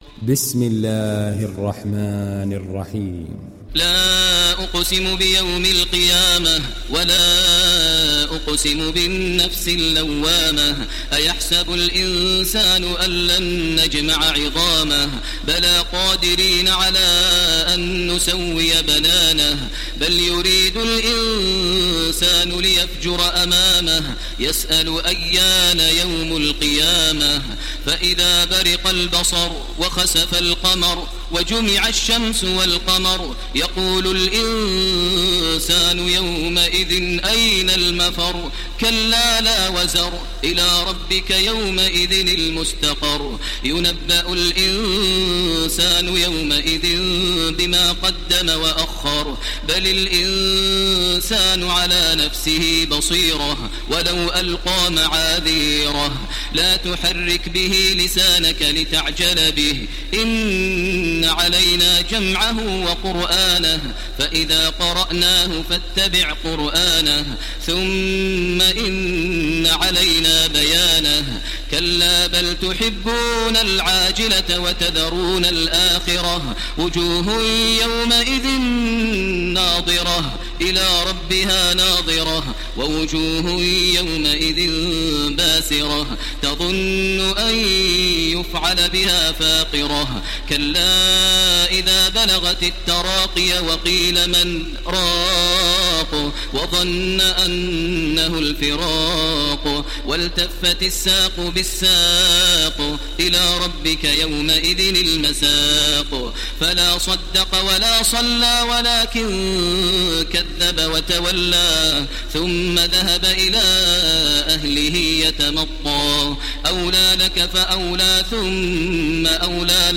Download Surat Al Qiyamah Taraweeh Makkah 1430